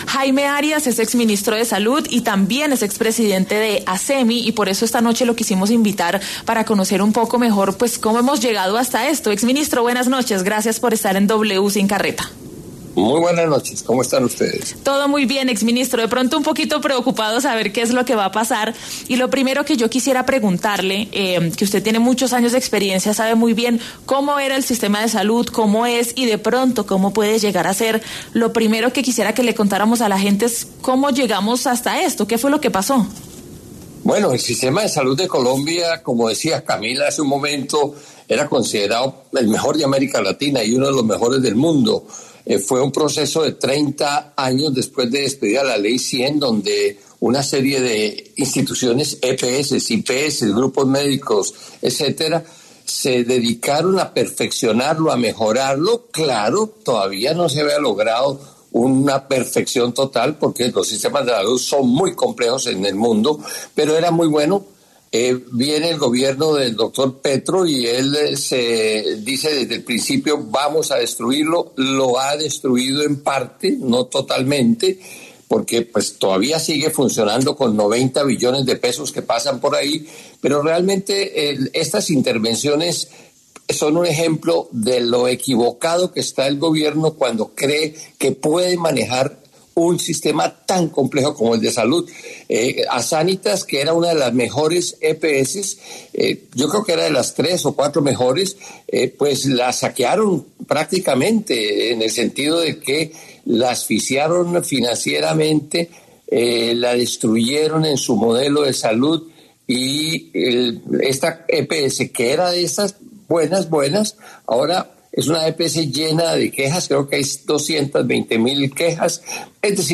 En conversación con W Sin Carreta, el exministro Jaime Airas aseguró que la intervención del Gobierno a la EPS Sanitas deterioró las finanzas, aumentó el endeudamiento y dañó el servicio de la entidad.
El exministro de Salud y expresidente de Acemi, Jaime Arias, pasó por los micrófonos de W Sin Carreta para hablar sobre el sistema de salud colombiano y la decisión de la Corte Constitucional de tumbar la intervención del Gobierno Petro a la EPS Sanitas.